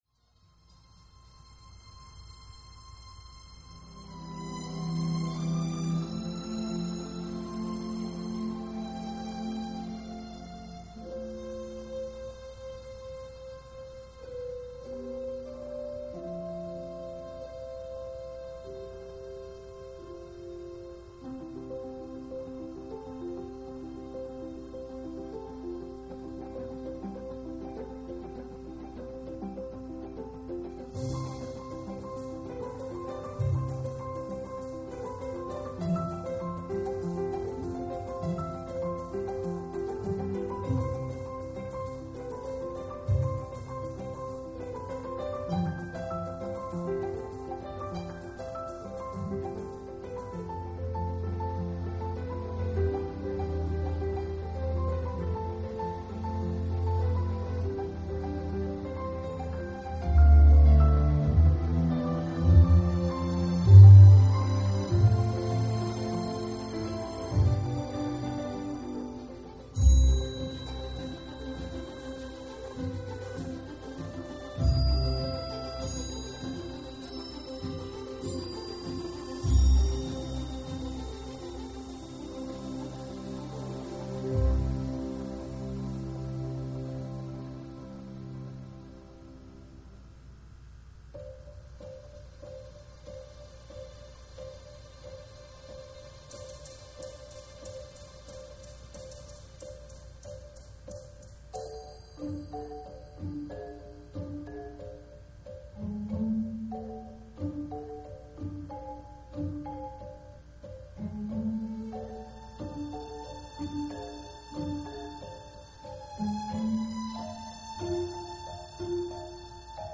Music clip two, 3'00" (1,370Kb) [end credits suite.